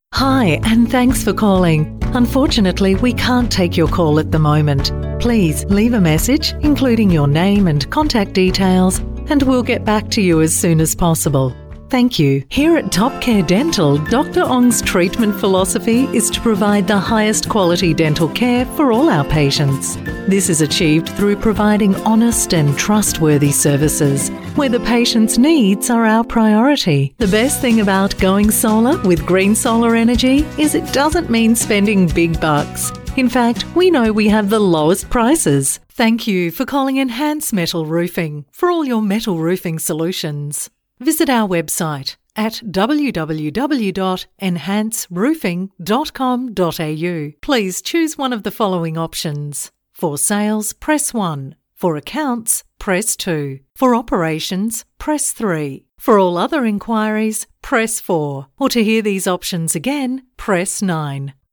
Female
Phone Greetings / On Hold
Message On Hold
Words that describe my voice are Natural, Warm, Australian Female Voice actor.